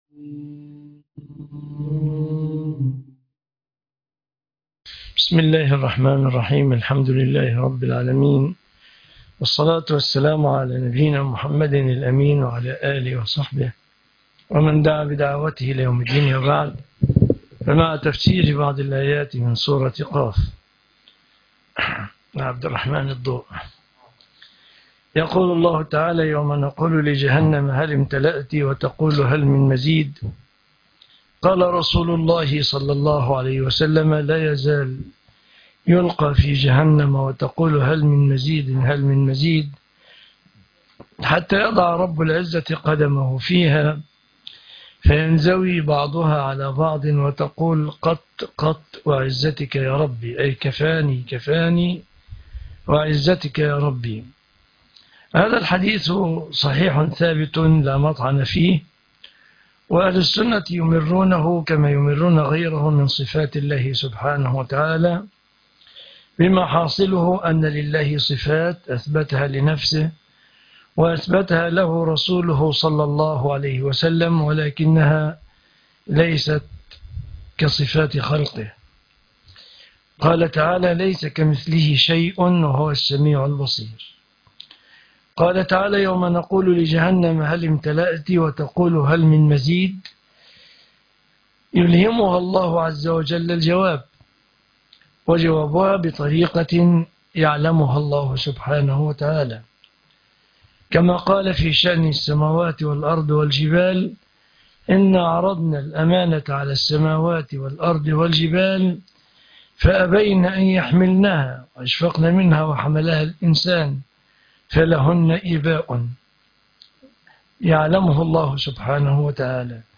سورة ق الجزء الثالث - تفسير القرآن الكريم